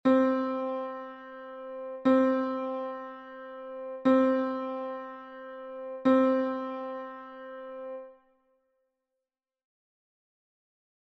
Listen to Middle C QUESTIONS YOU MIGHT ASK Do I need to read music?
MiddleC.mp3